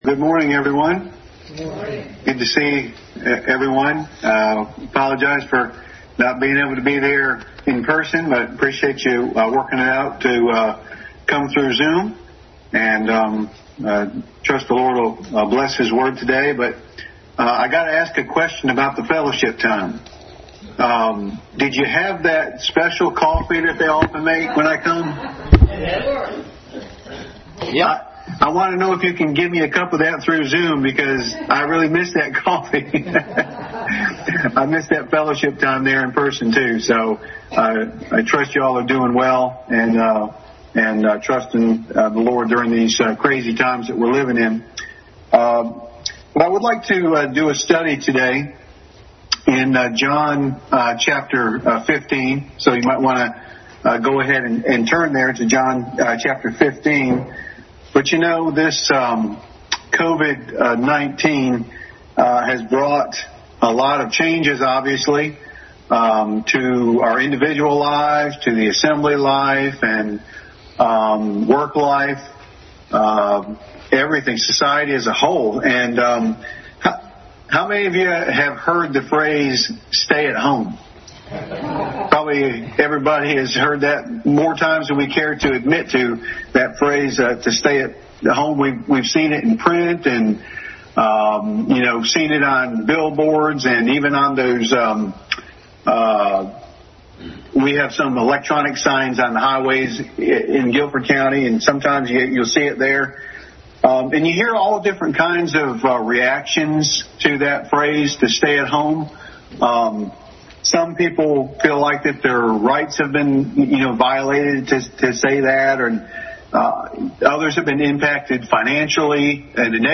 Adult Sunday School Class.